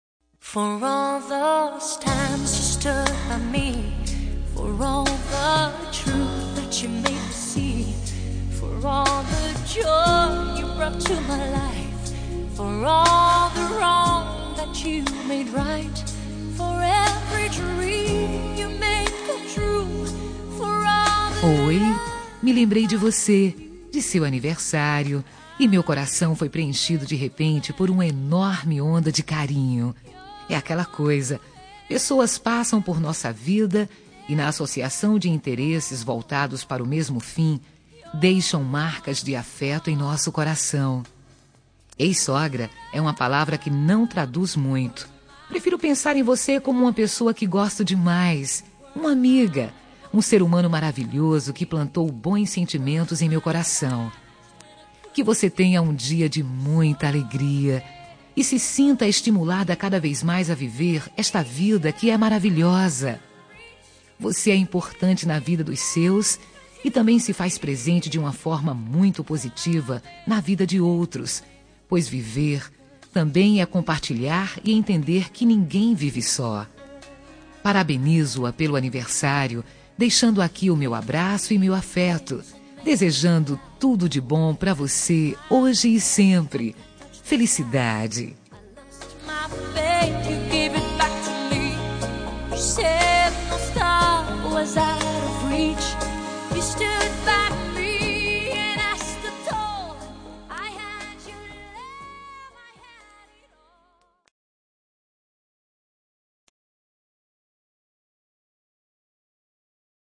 Telemensagem Aniversário de Sogra – Voz Feminina – Cód: 1971 – Ex-sogra